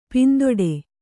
♪ pindoḍe